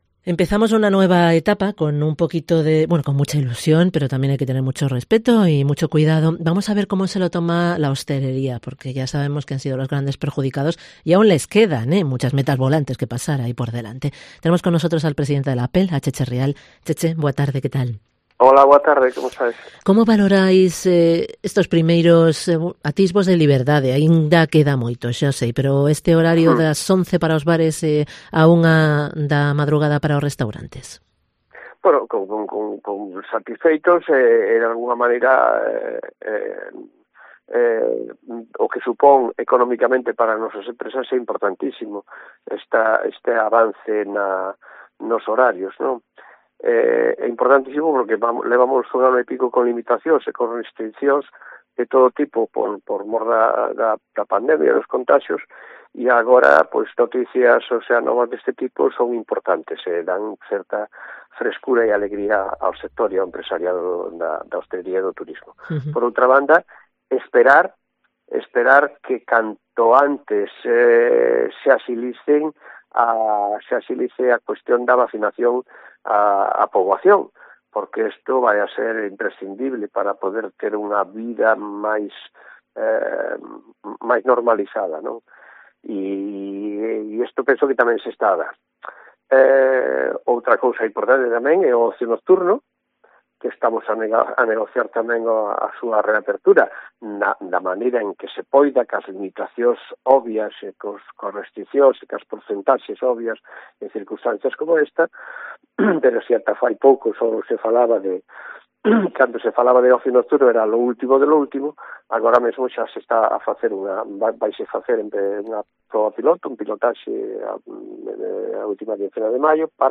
En esta entrevista, cuenta que cada conquista que se alcance será positiva pero no se tiene nada garantizado hasta que la vacunación llegue a la mayor parte de la población . Ve positivo que ya esté sobre la mesa la posibilidad de recuperar el ocio nocturno .